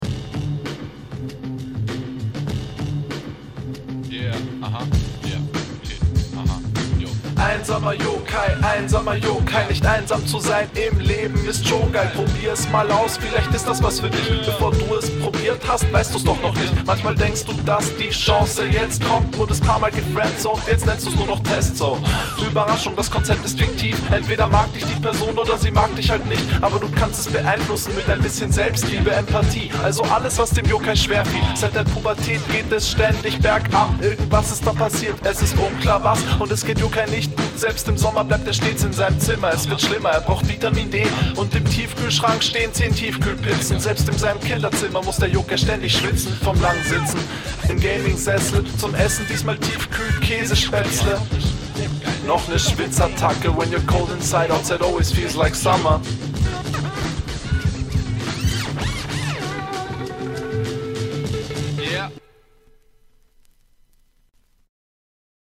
GEILER BEAT! oldschool-vibez hitten nice!